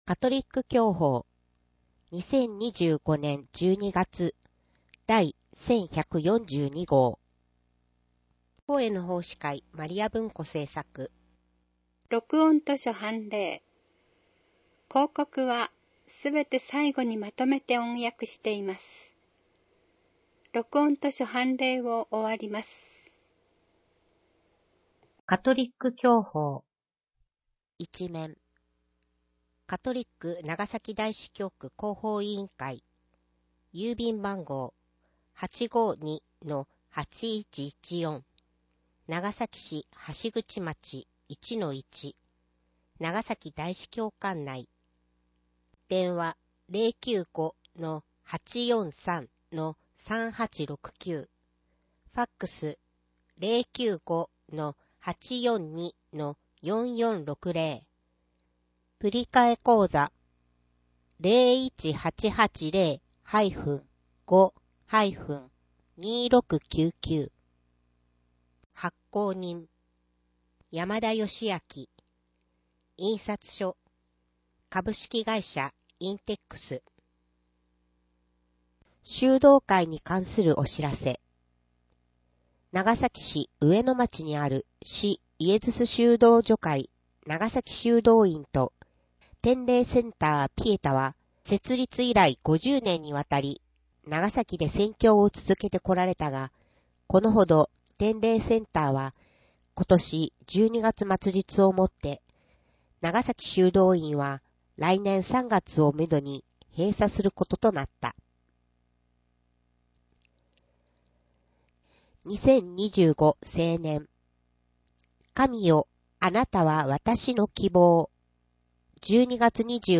【音声訳】2025年12月号